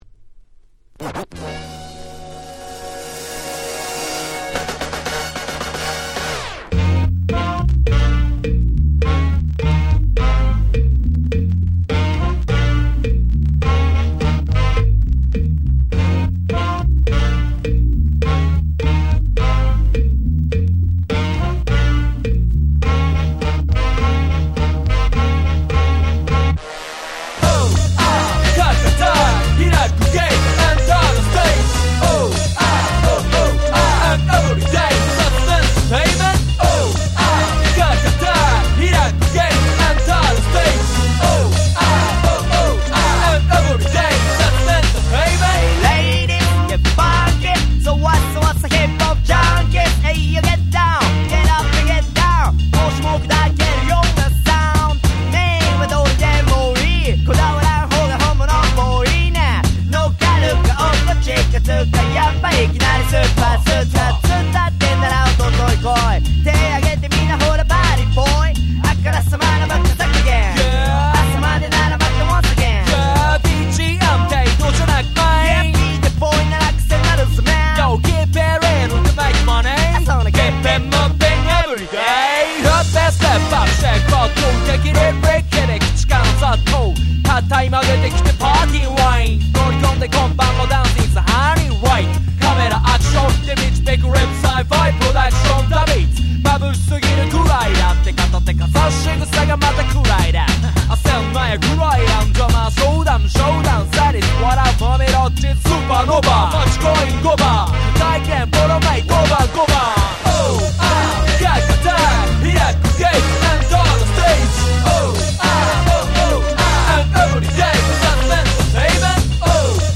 Japanese Hip Hop Classics !!